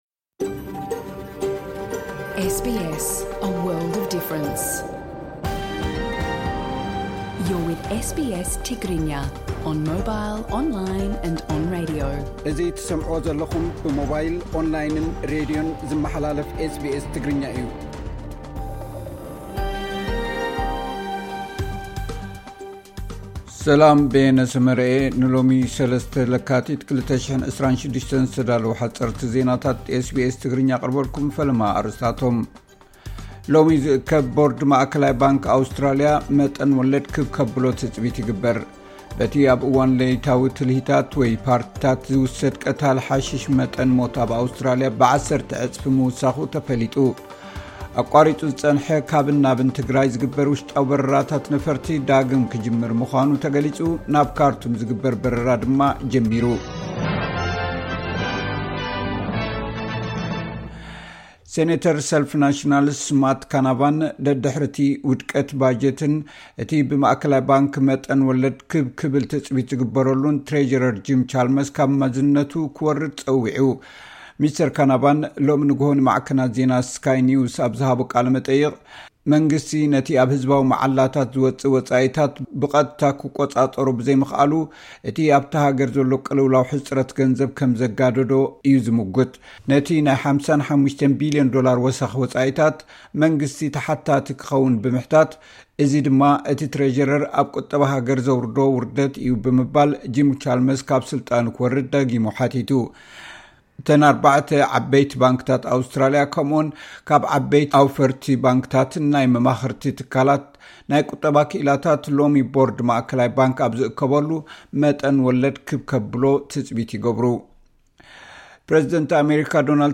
ሓጸርቲ ዜናታት ኤስ ቢ ኤስ ትግርኛ (03 ለካቲት 2026)